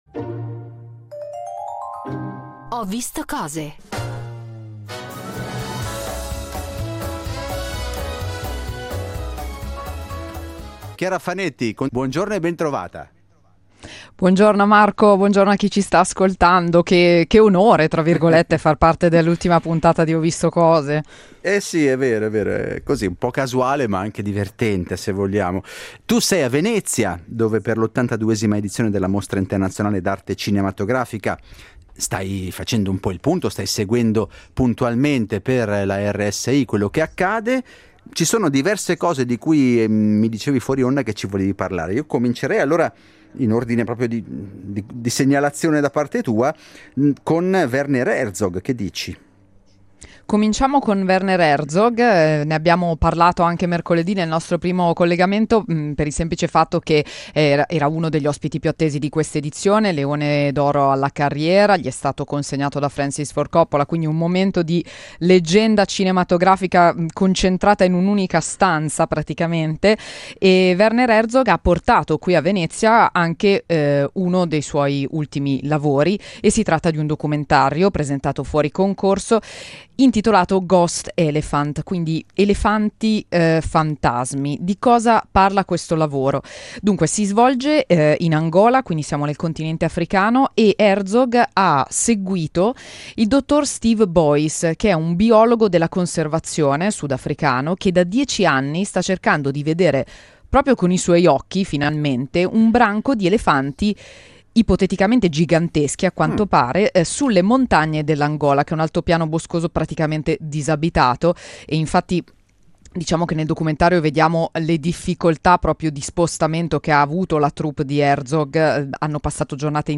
“Ho visto cose”, in diretta da Venezia